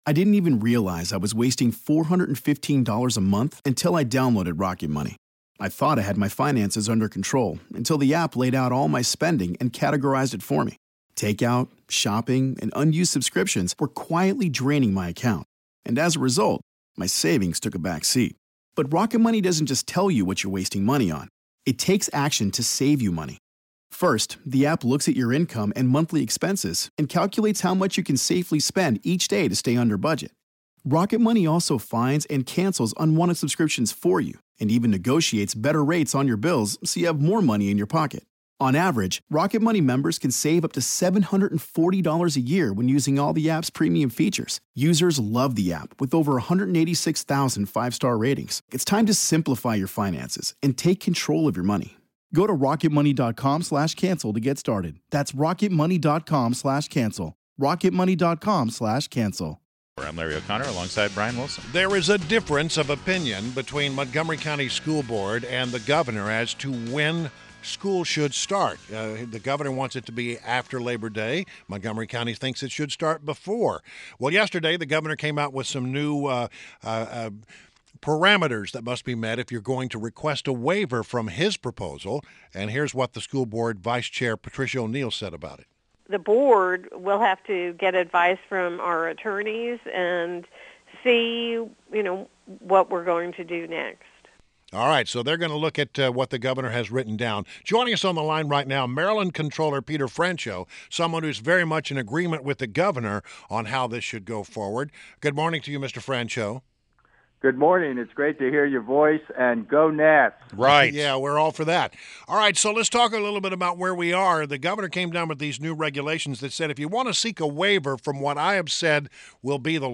INTERVIEW -- MARYLAND COMPTROLLER PETER FRANCHOT